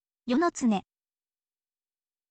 yo no tsune